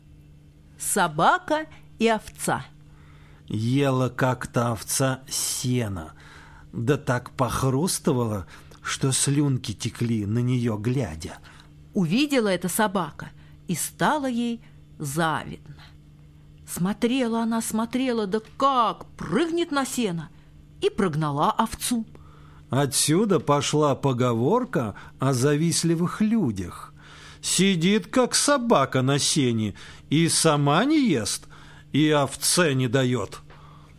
Собака и овца - латышская аудиосказка - слушать онлайн